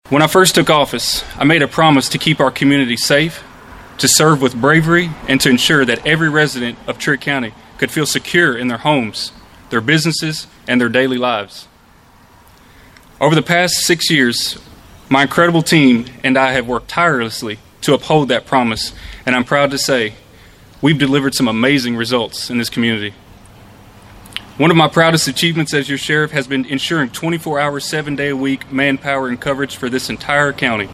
Acree made the announcement in front of the Trigg County Justice Center before a large crowd that included deputies, community members, and other supporters.